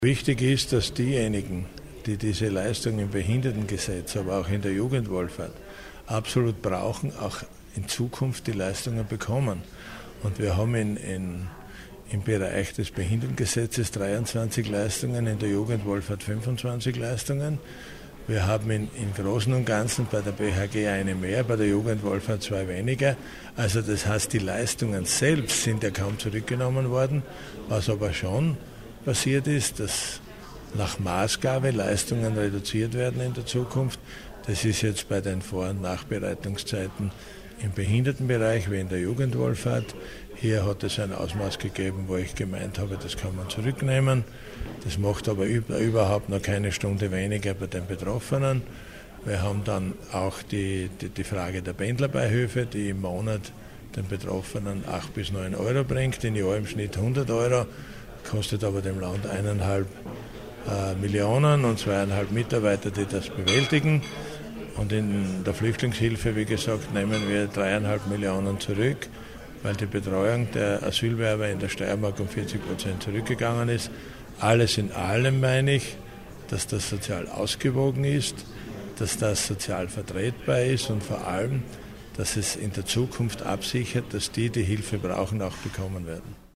O-Töne LH-Stv. Siegfried Schrittwieser: